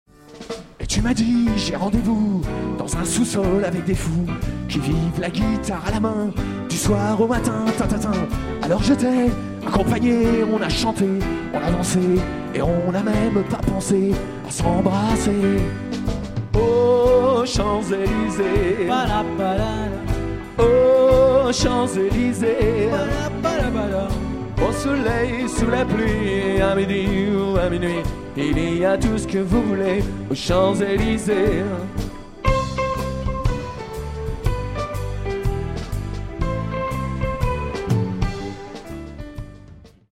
madison